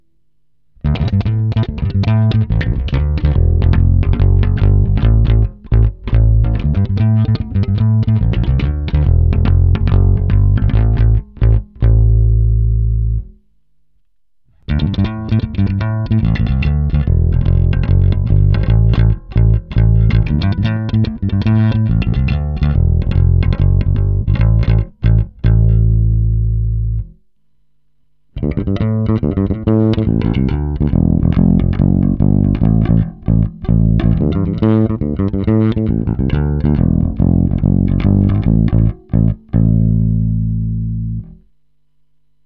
Všechny následující ukázky jsou s plně otevřenou tónovou clonou.
Nejdříve prakticky skoro čistý zvuk v pořadí krkový snímač, oba snímače, kobylkový snímač.
Zvuk krkového snímače je mohutný s bohatým dřevním charakterem.